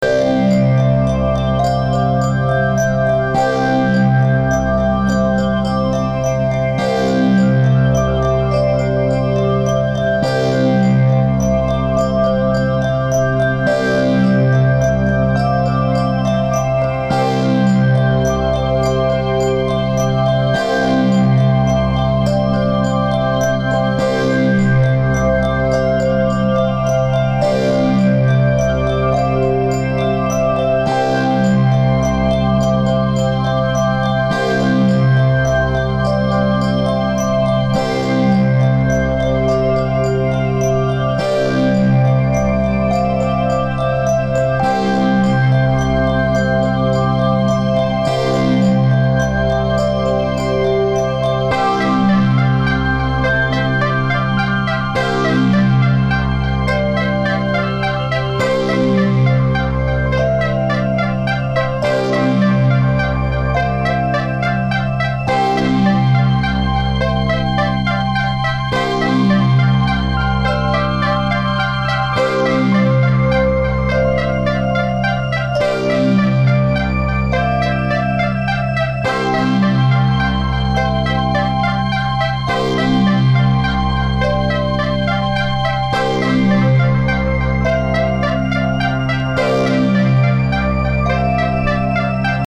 Five tracks of drone and loopy minimalism